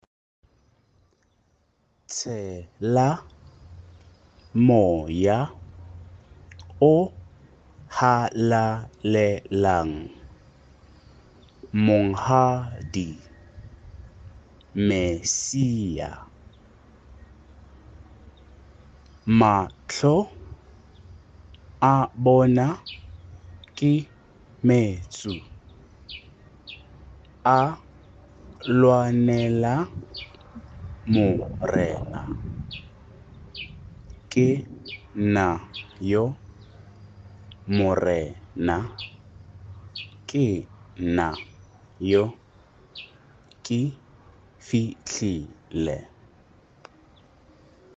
Tshela Moya / Ke nna yo Morena (Laudate)- Director’s Score- Practice Tracks- Reference Video- Pronunciation Guide
Tshela Moya Pronunciation.mp3